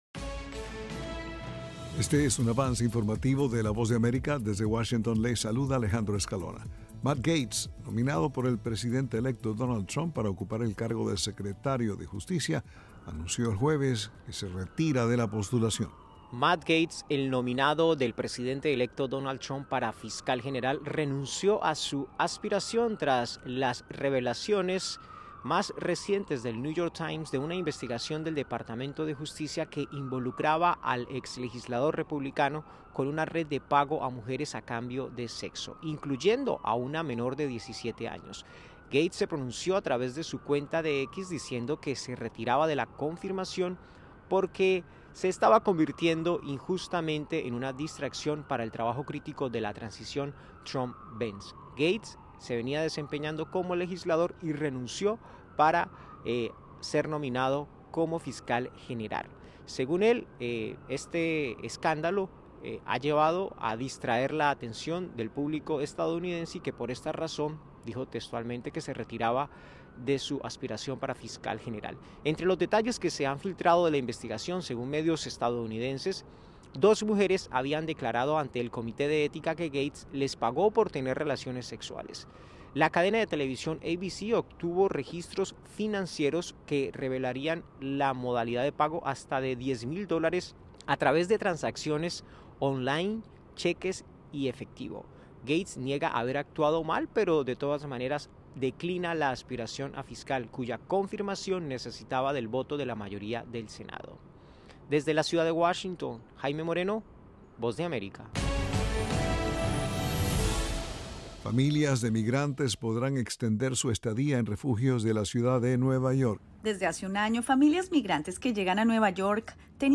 Avance Informativo
éEste es un avance informativo presentado por la Voz de America.